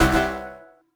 Buzz Error (4).wav